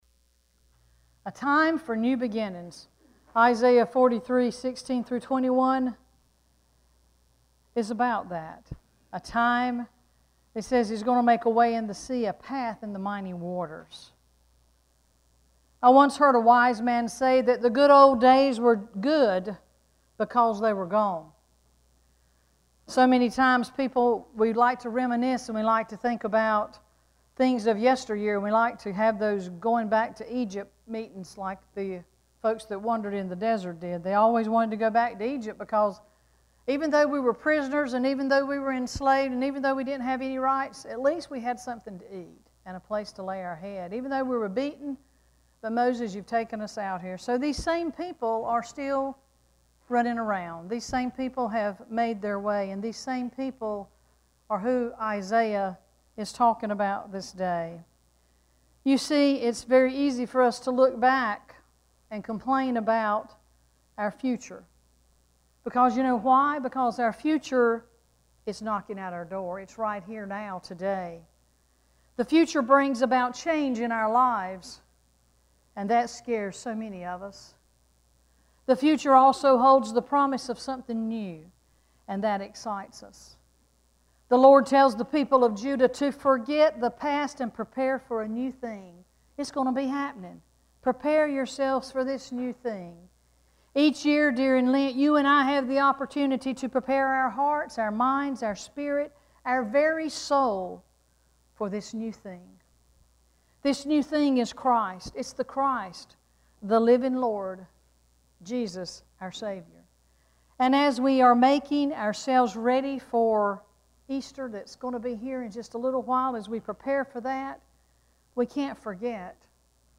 Sermon
3-13-sermon.mp3